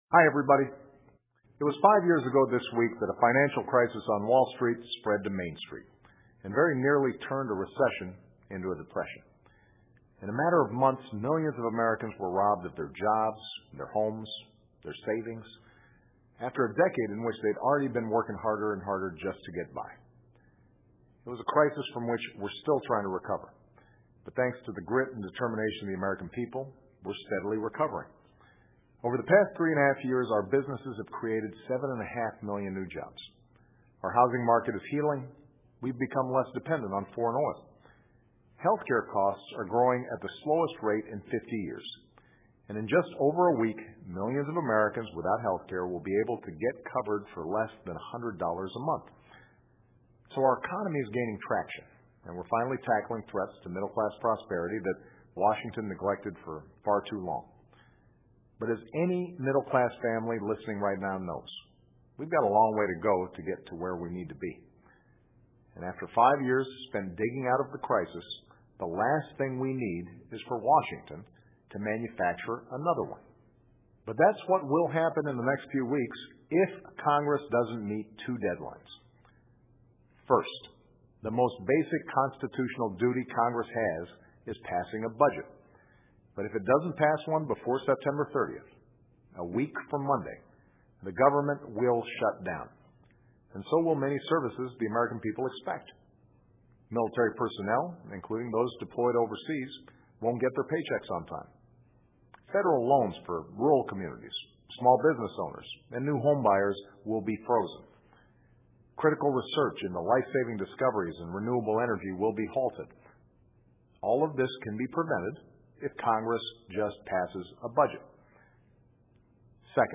奥巴马每周电视讲话：总统呼吁国会尽早通过预算法案 听力文件下载—在线英语听力室